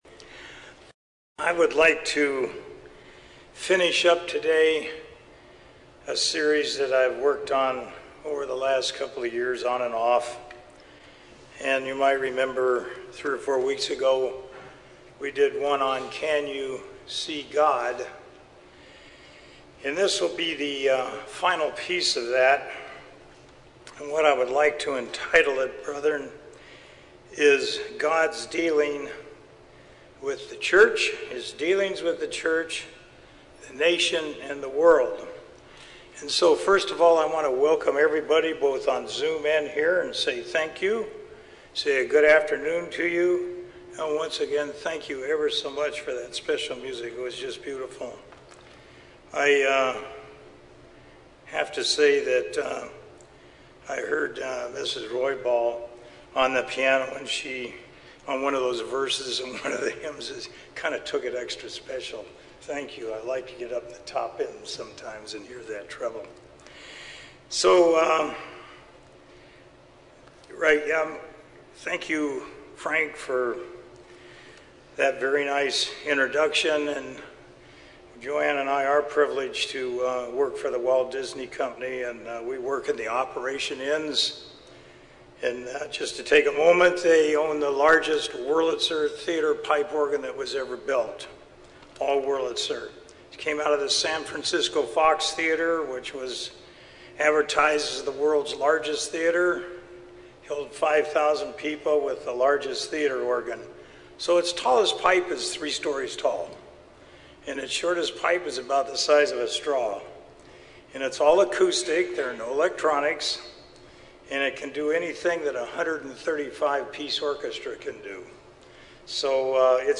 This sermon delves into various methods God uses in dealing with His creation.